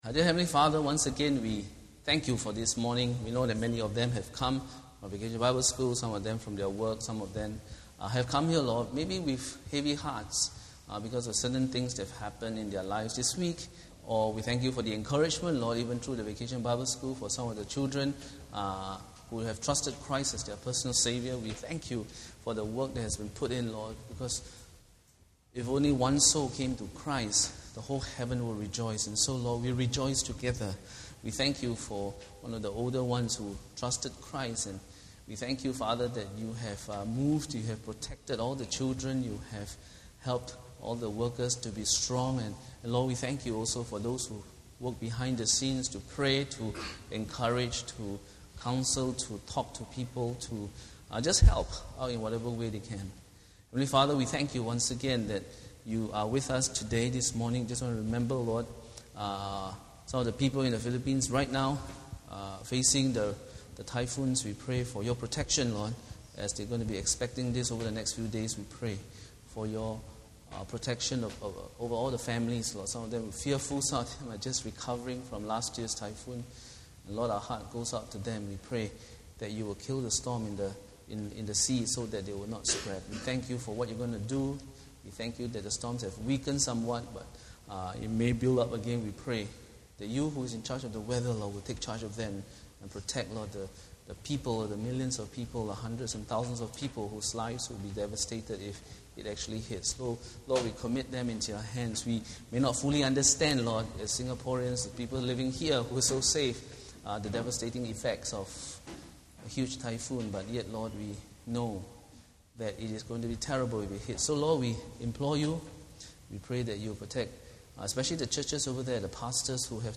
If you don’t know if you’re saved, can you help people spiritually? Find out in today’s sermon.